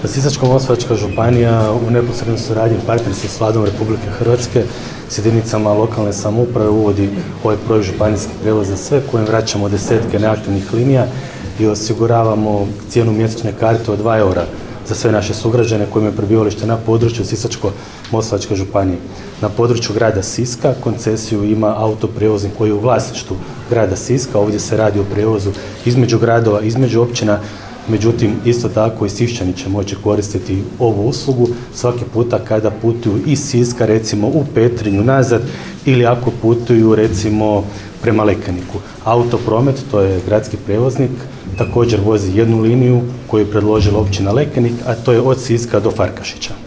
Župan Sisačko-moslavačke županije Ivan Celjak pojasnio je značaj ovoga ugovora, odnosno projekta „Županijski prijevoz za sve“